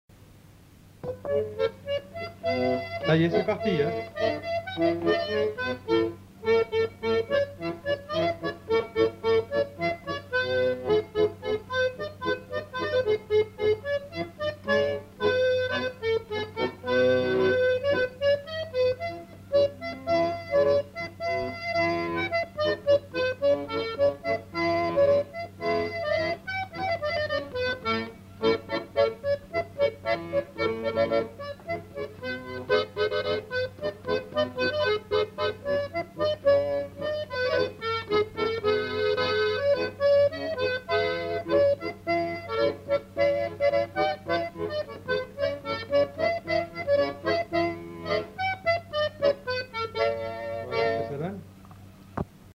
Instrumental. Accordéon diatonique
Lieu : Monclar d'Agenais
Genre : morceau instrumental
Instrument de musique : accordéon diatonique